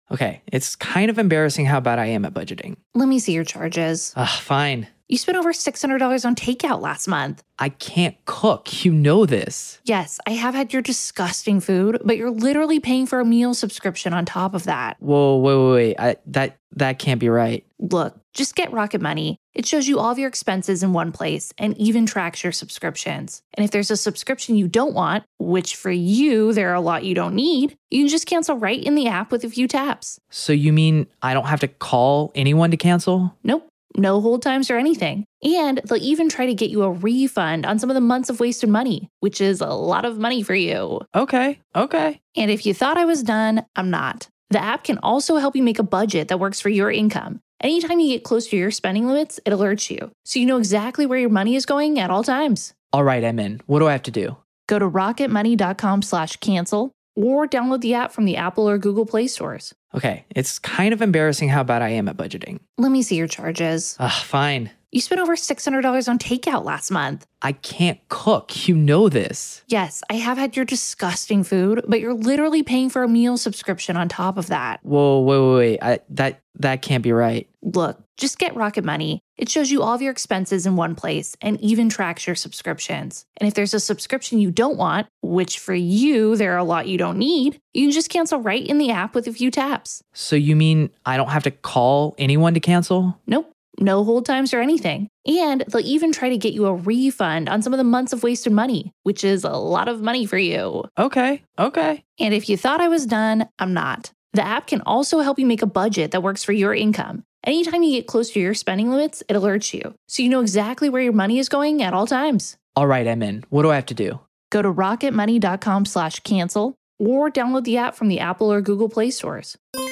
HHS Interview with Tommy Chong
Our exclusive interview with Cheech & Chong's Tommy Chong.